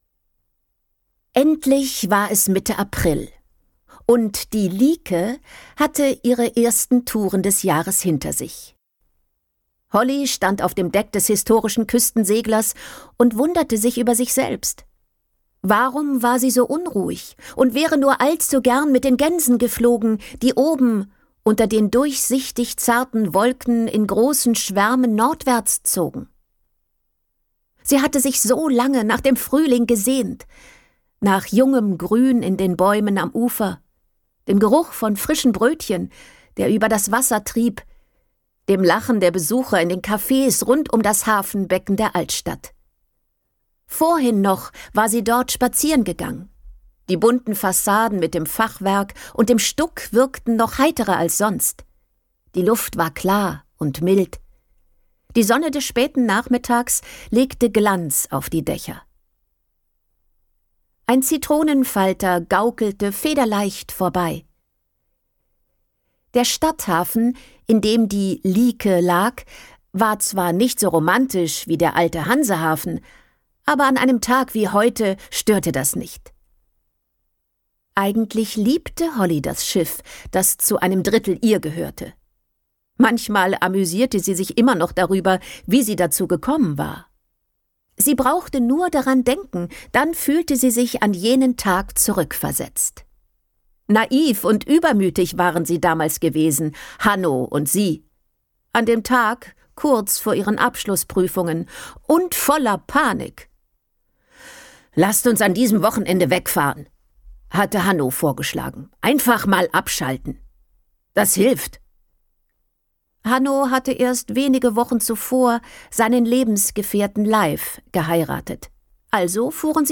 Der Wind in unseren Segeln - Patricia Koelle | argon hörbuch
Gekürzt Autorisierte, d.h. von Autor:innen und / oder Verlagen freigegebene, bearbeitete Fassung.